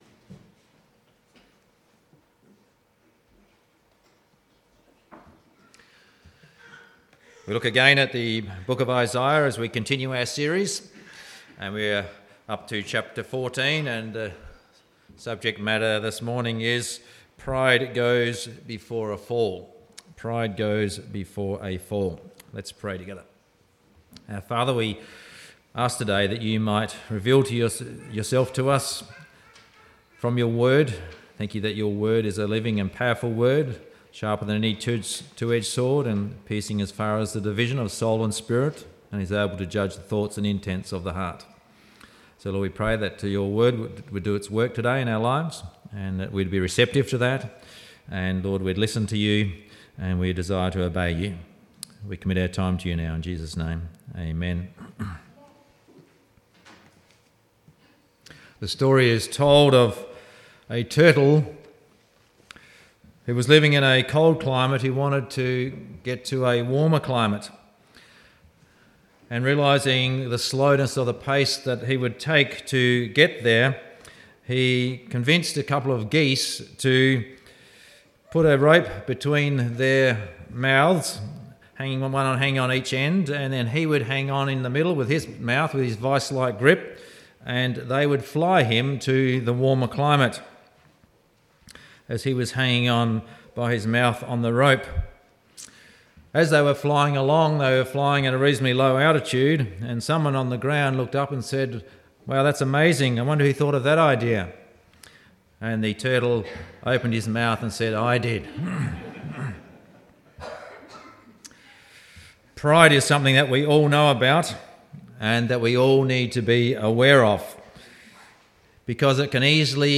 27.8.17-Sunday-Service-Pride-Goes-Before-a-Fall.mp3